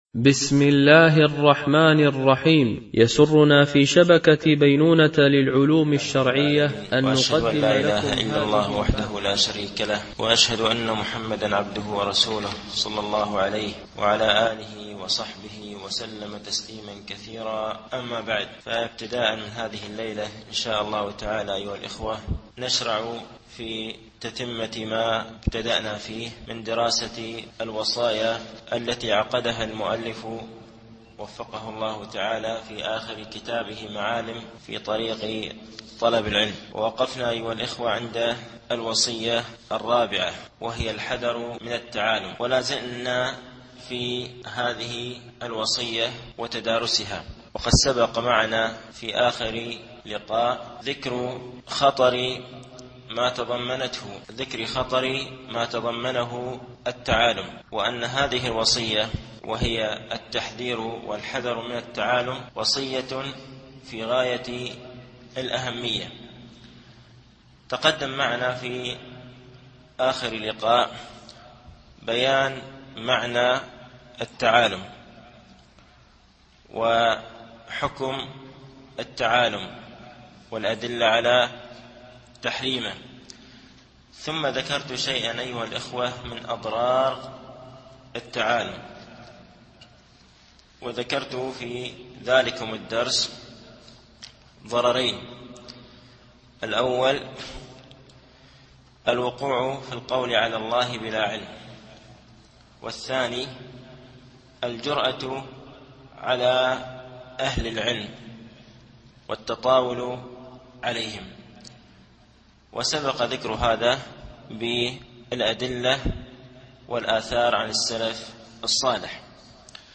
التعليق على كتاب معالم في طريق طلب العلم (وصية4موقف طالب العلم من التعالم2) - الدرس الثاني و الأربعون